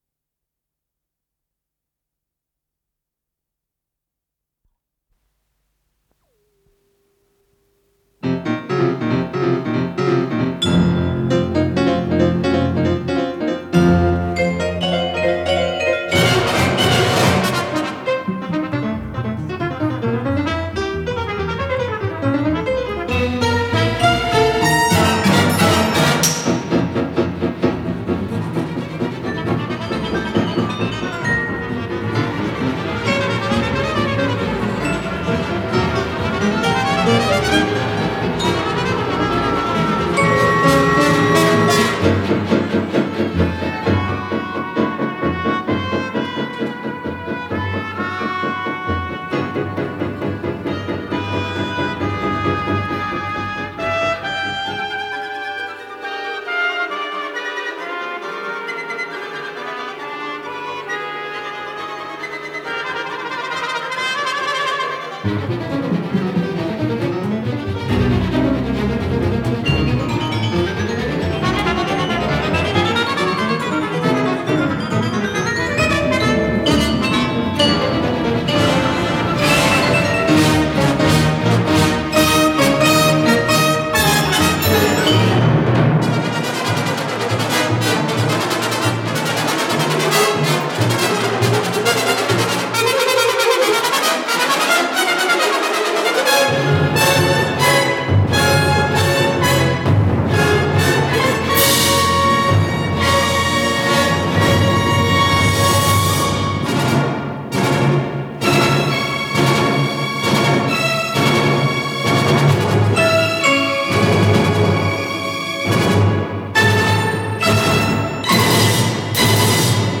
рояль
труба
Кончерто гроссо, без определенной тональности, одночастный